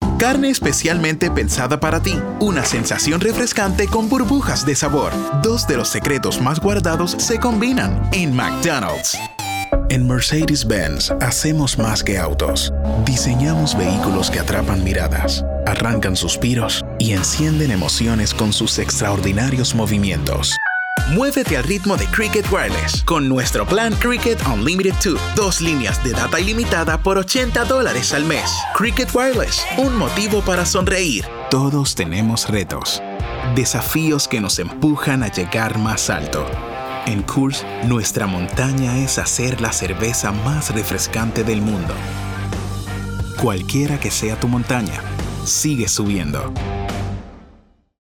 Spanish Commercial